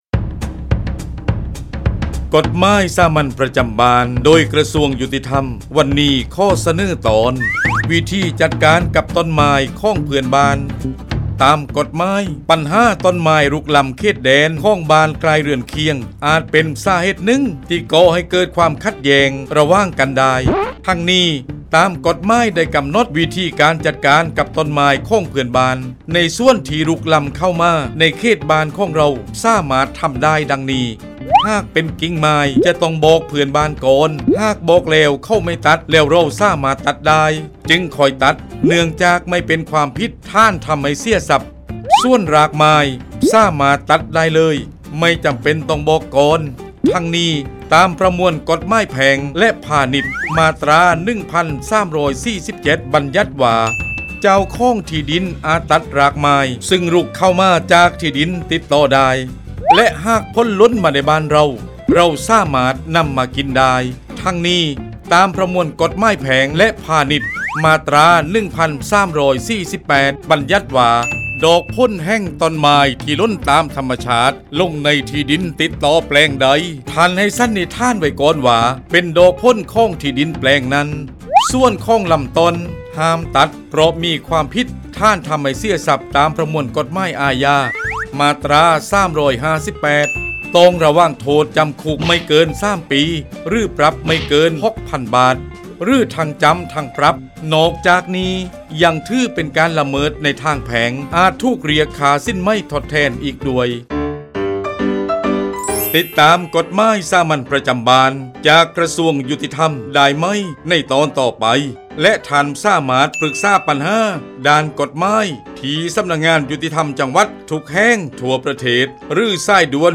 กฎหมายสามัญประจำบ้าน ฉบับภาษาท้องถิ่น ภาคใต้ ตอนวิธีจัดการกับต้นไม้ของเพื่อนบ้านตาม
ลักษณะของสื่อ :   บรรยาย, คลิปเสียง